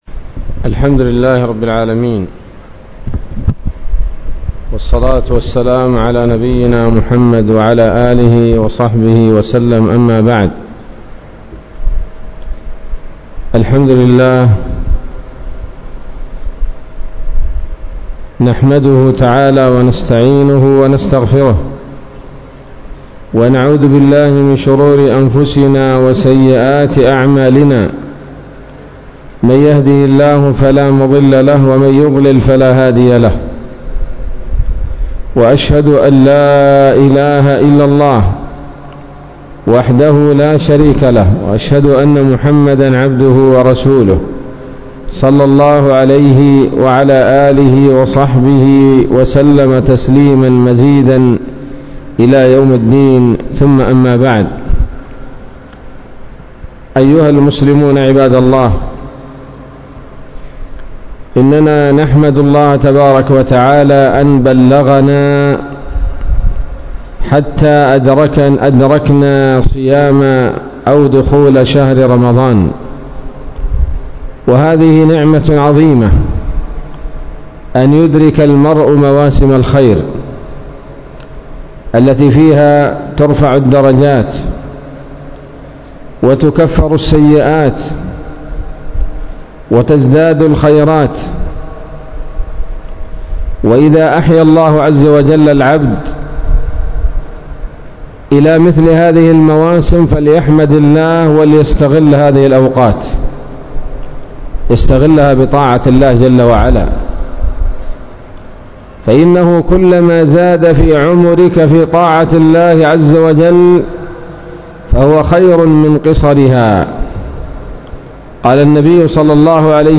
محاضرة بعنوان: (( بعض أخطاء الصائمين 1 )) عصر يوم الجمعة، 3 رمضان 1444 هـ، بمسجد عثمان بن عفان - قرية قرو